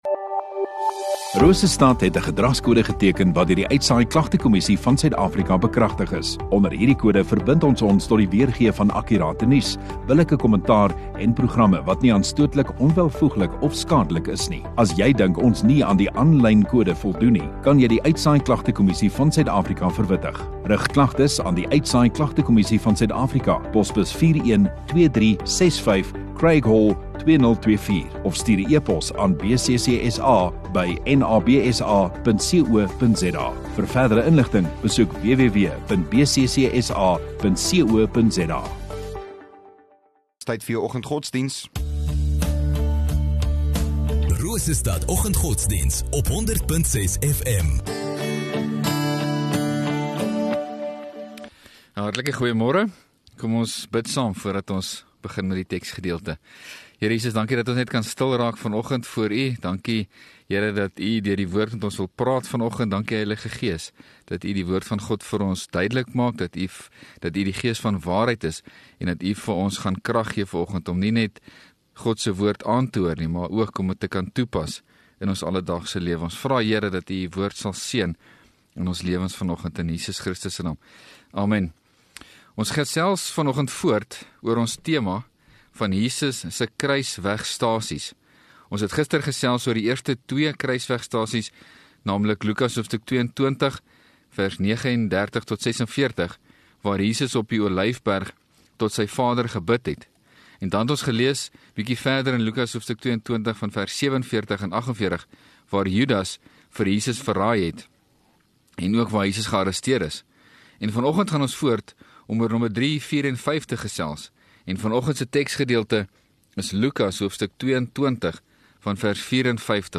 4 Mar Woensdag Oggenddiens